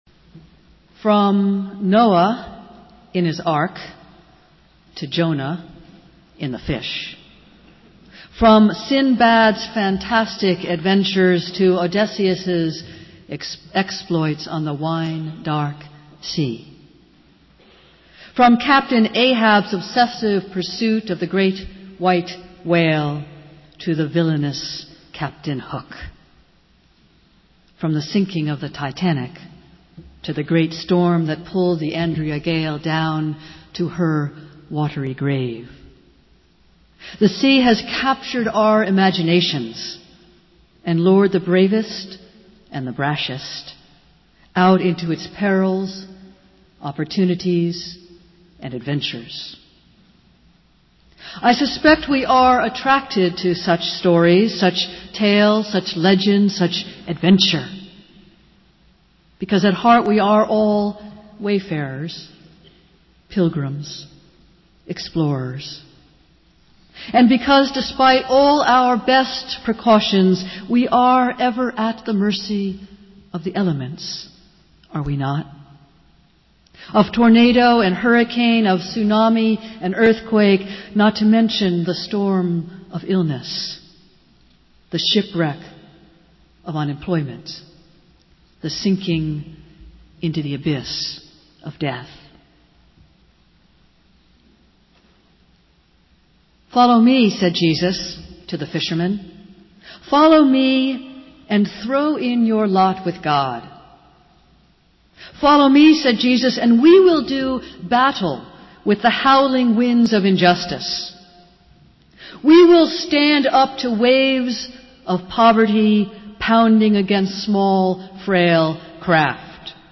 Festival Worship - Fourth Sunday of Easter